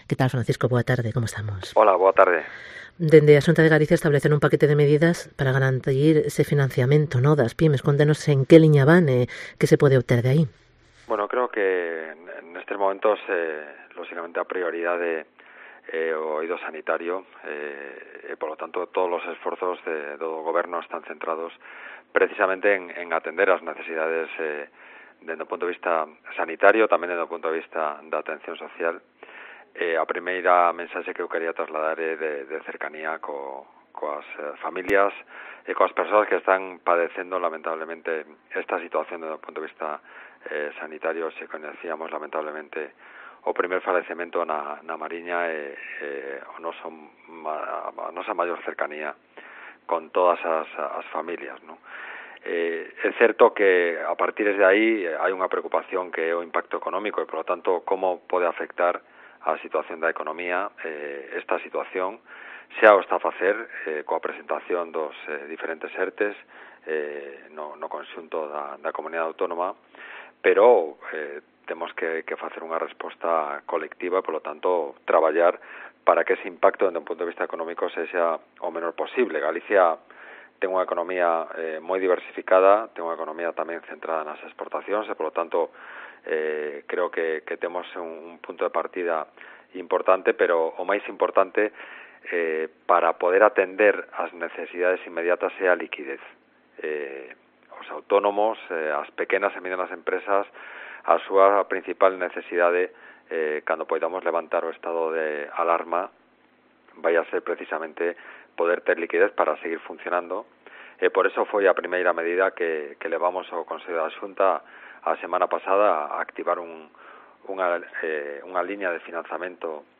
ENTREVISTA con Francisco Conde, conselleiro de Economía de la Xunta de Galicia